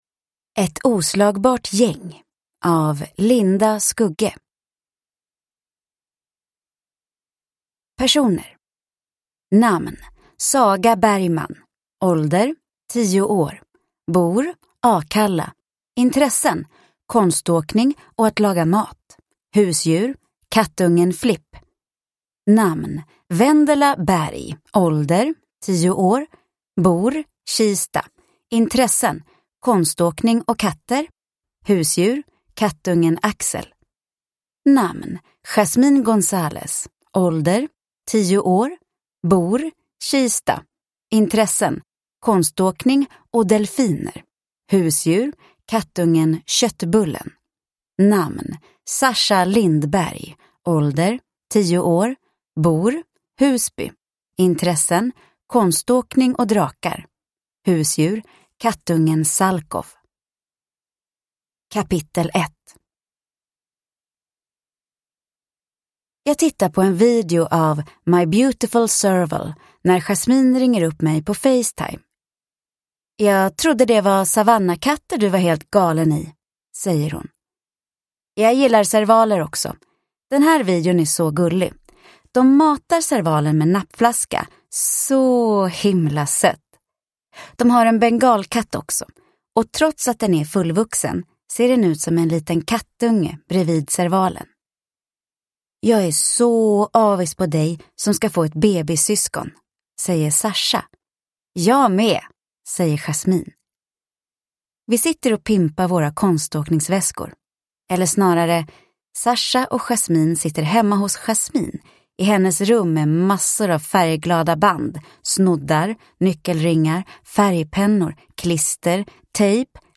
Ett oslagbart gäng – Ljudbok – Laddas ner